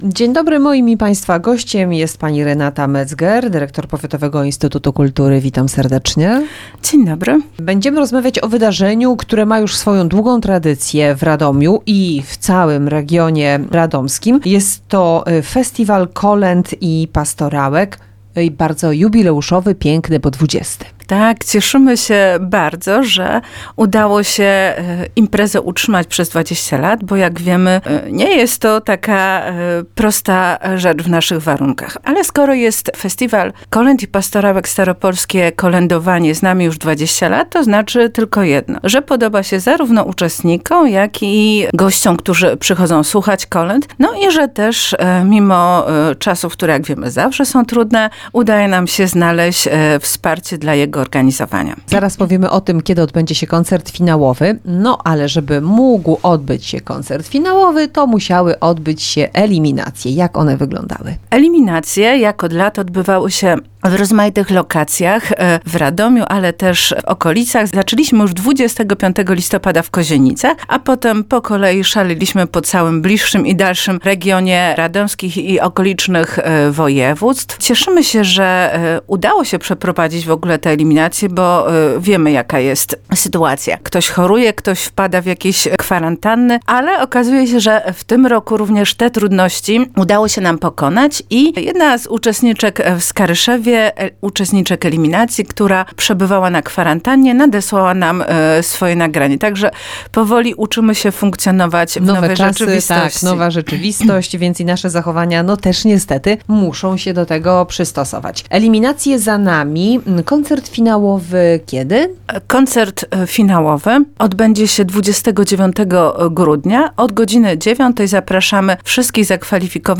Magazyn SamorządowyWiadomości RadomWydarzenie KulturalneWywiad